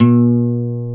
Index of /90_sSampleCDs/Roland L-CD701/GTR_Nylon String/GTR_Nylon Chorus
GTR BRTNYL01.wav